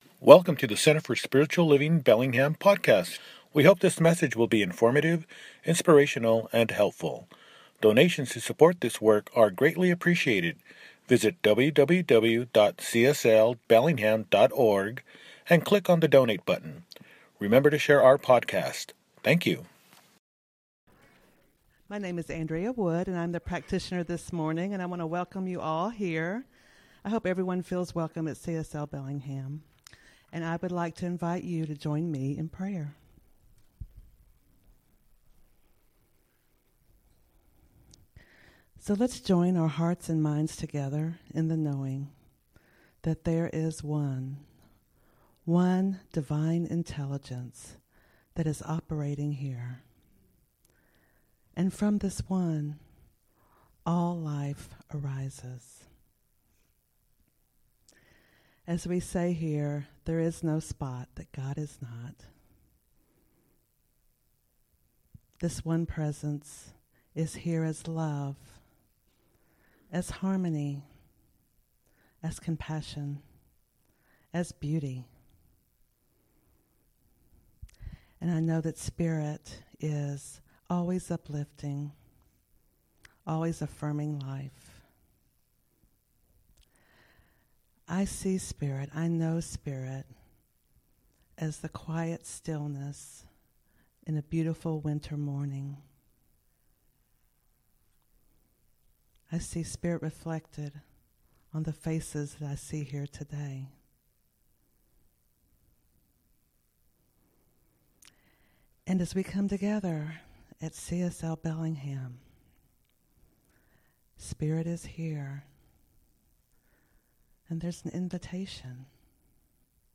Dec 10, 2023 | Podcasts, Services